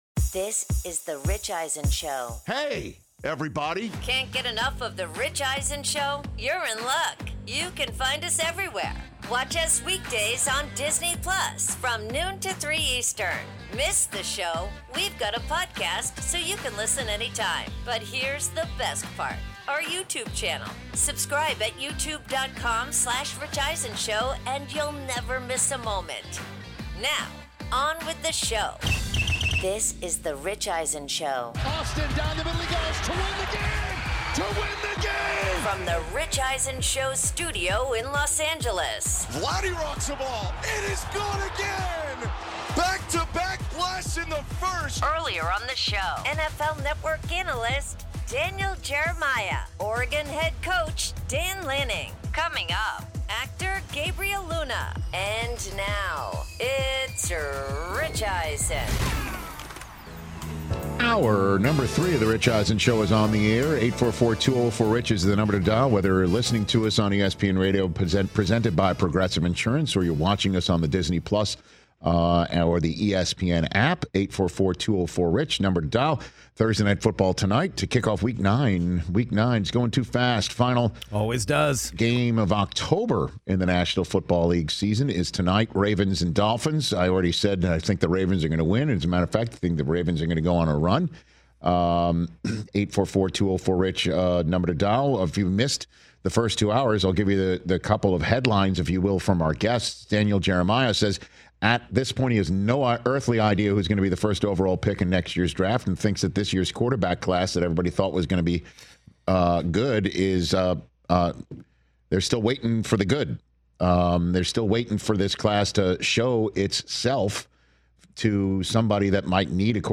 Hour 3: NFL Week 9 'Higher Register,' plus Actor Gabriel Luna In-Studio